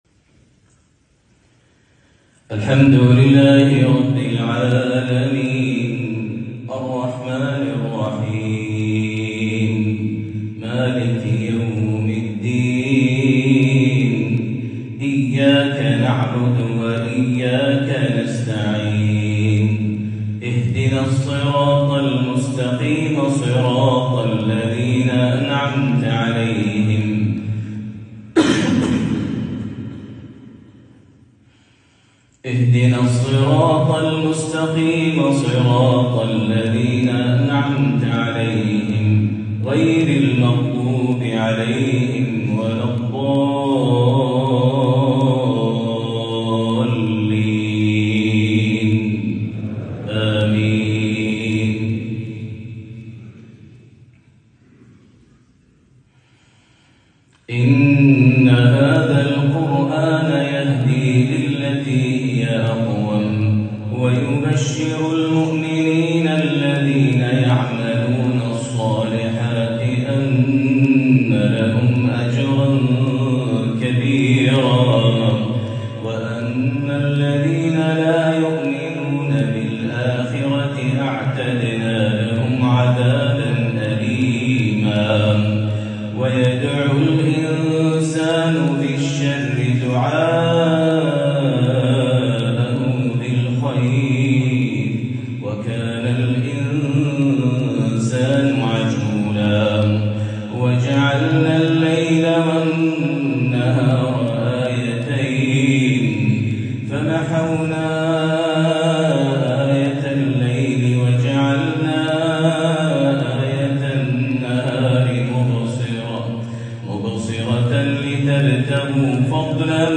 تلاوة من سورة الإسراء من مسجد الجديد في سكوبيه | فجر الأحد 16 جمادى الآخرة 1447هـ > زيارة الشيخ د. ماهر المعيقلي إلى مقدونيا الشمالية 1447هـ > تلاوات و جهود الشيخ ماهر المعيقلي > المزيد - تلاوات الحرمين